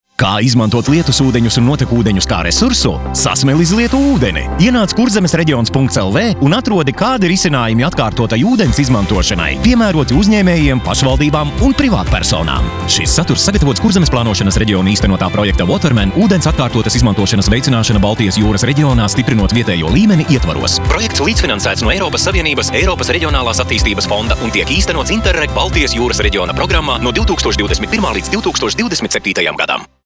Radio reklāmas
• Radio klips Nr.1 – aicinājums uz kampaņas atklāšanas pasākumu (MP3)